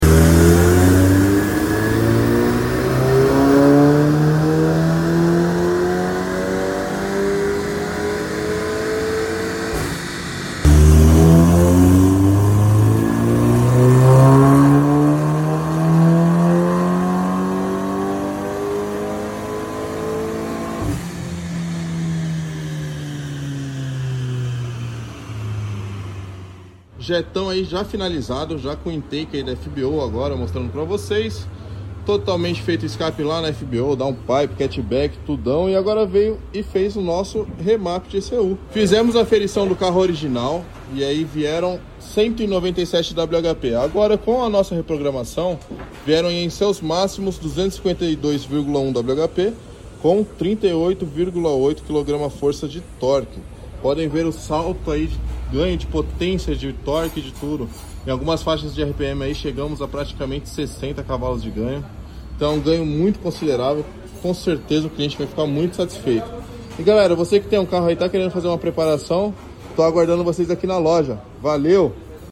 VW JETTA 200CV | STAGE sound effects free download